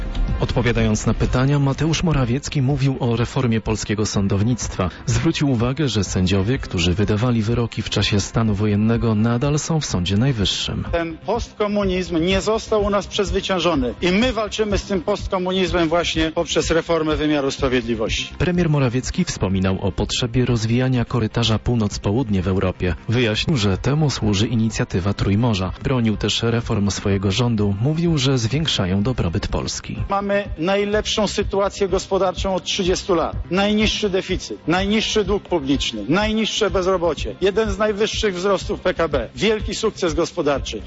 Przedstawiając w Parlamencie Europejskim polską propozycję rozwoju Wspólnej Europy, polski premier przedstawił trzy najważniejsze kierunki.